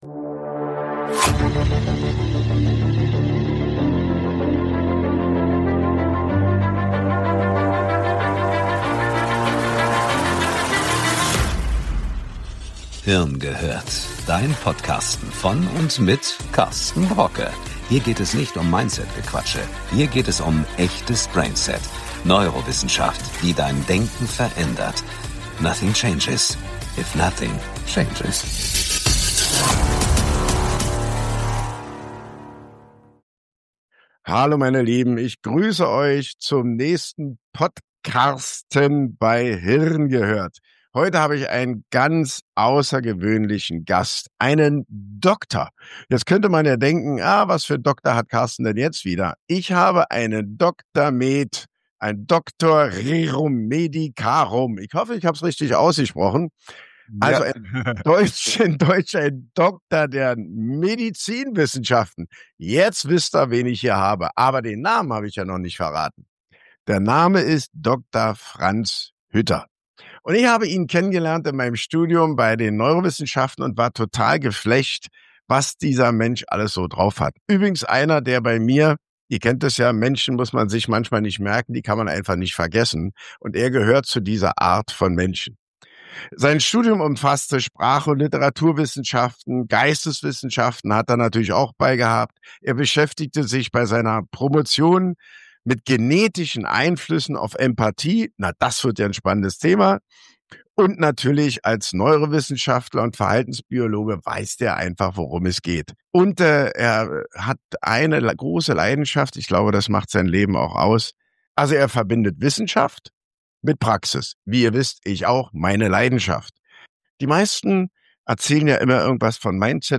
im Dialog